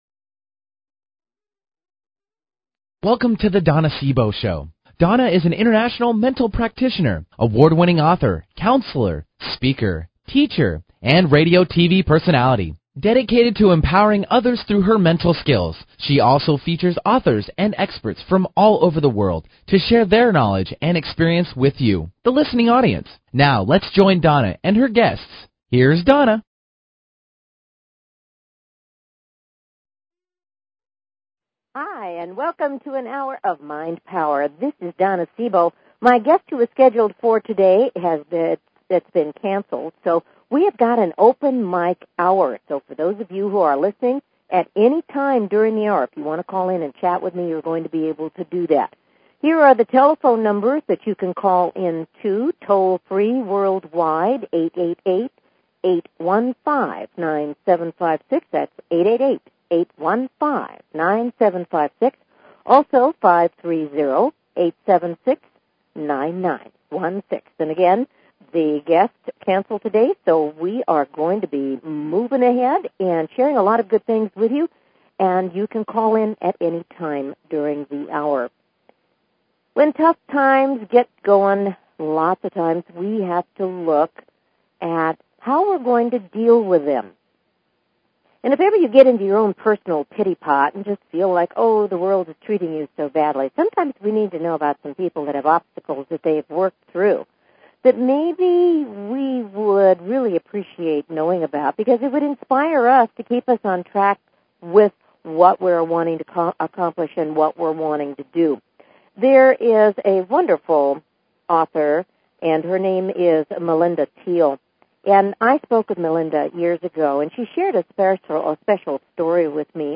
Talk Show Episode, Audio Podcast
This was an open mike hour.